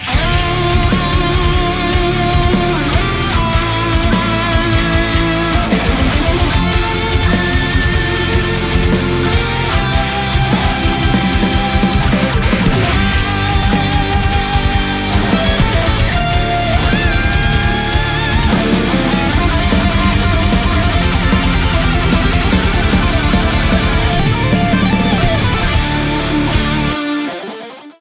• Качество: 320, Stereo
ритмичные
громкие
брутальные
без слов
инструментальные
электрогитара
heavy Metal
эпичные
тяжелый рок
Метал-кавер